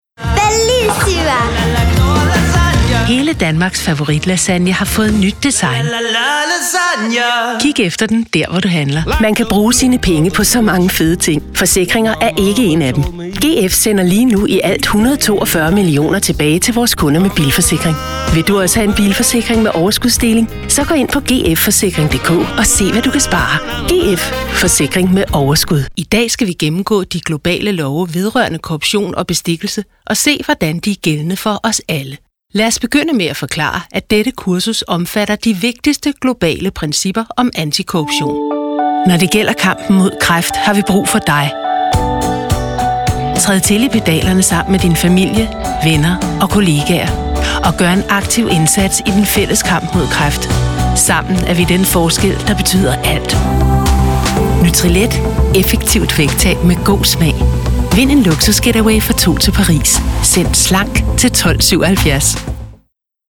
Yabancı Seslendirme Kadrosu, yabancı sesler